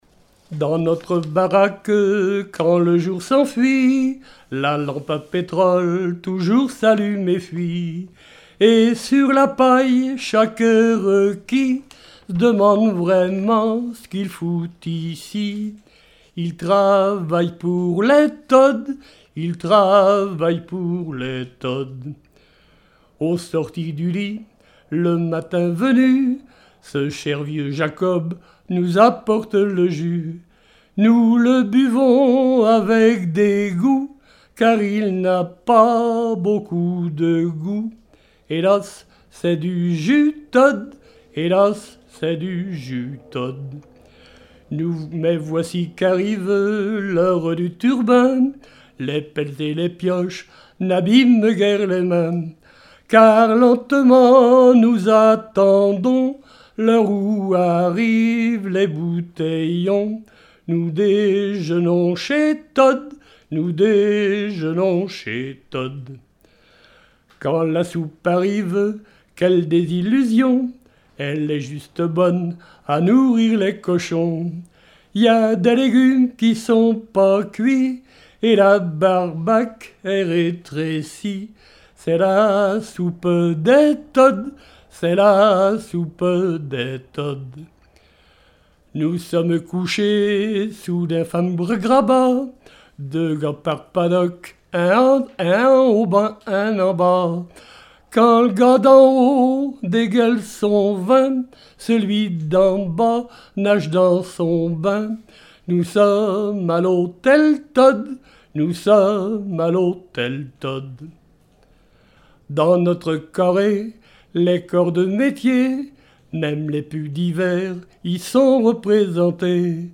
Chansons traditionnelles et populaire
Pièce musicale inédite